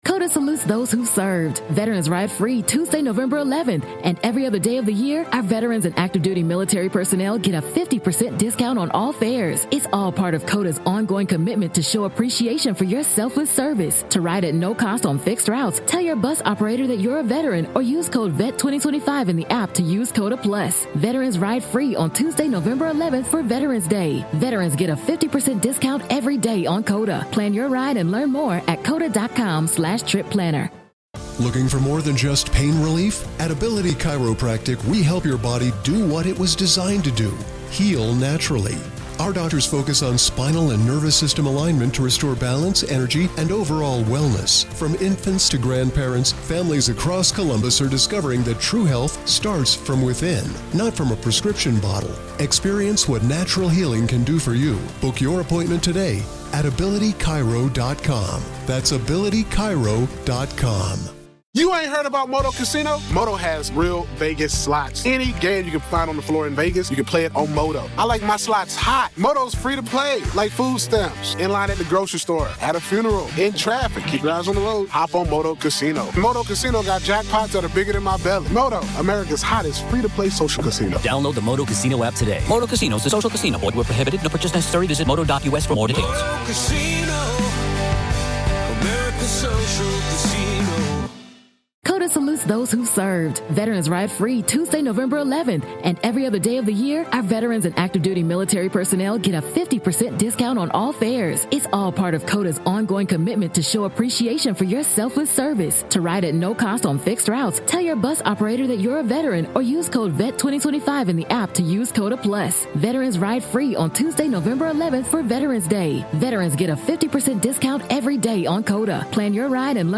When I tell you this episode had us HYSTERICALLY laughing